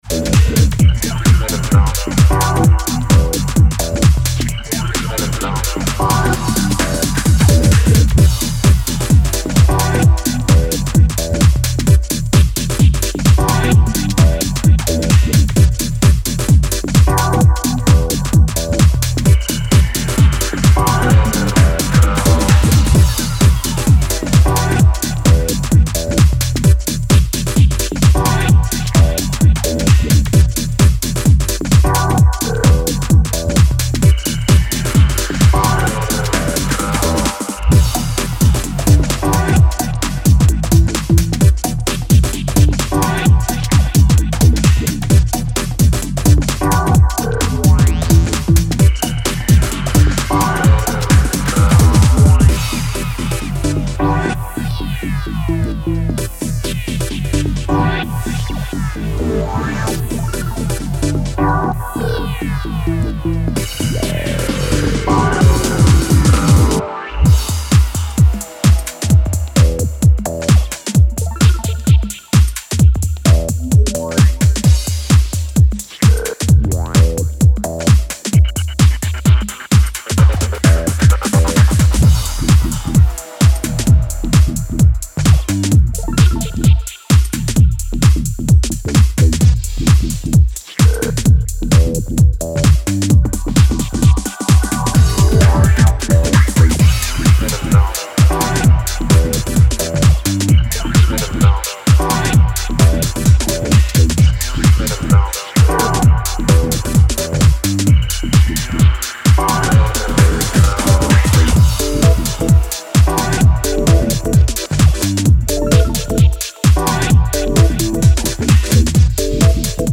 A flawless fusion of speed garage, house, and breakbeats.